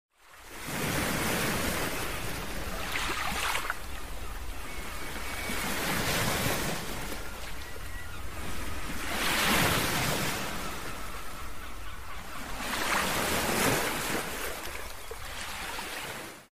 Not just a plugin. It’s the unmistakable sound of the ukulele at your fingertips. ACOUSTIC SERIES UKULELE.